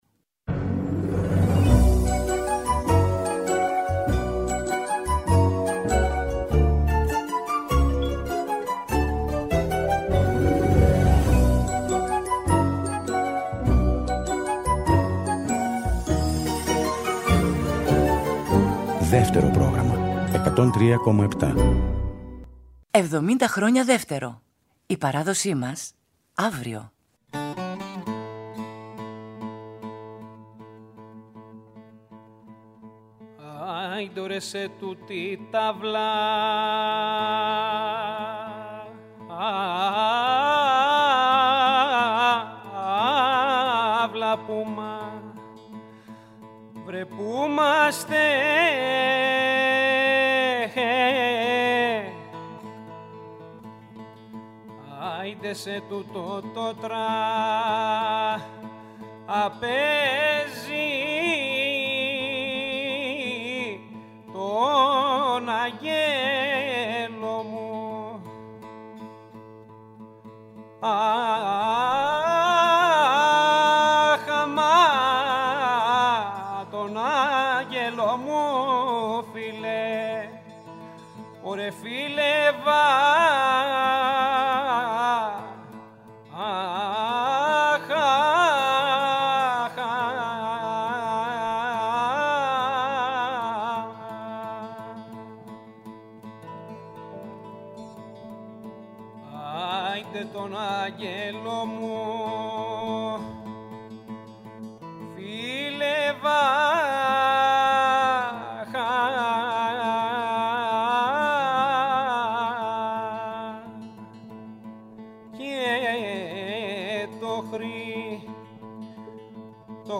με ζωντανές ηχογραφήσεις σε Αθήνα και Θεσσαλονίκη
Σαντούρι
Βιολί
Πνευστά
Λαούτο
Κρουστά